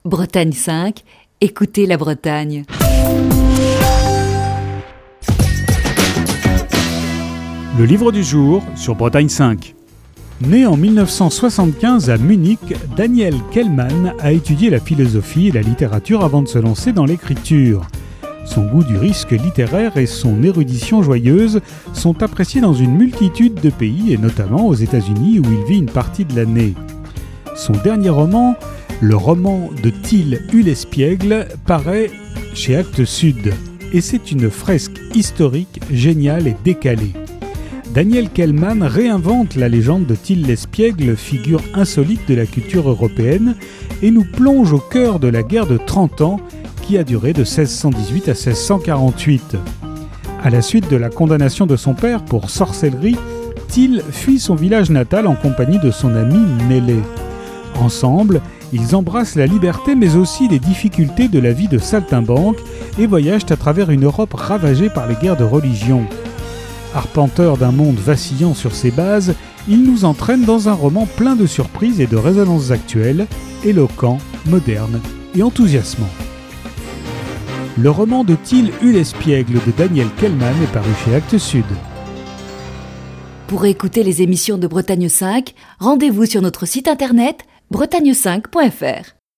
Chronique du 26 février 2020.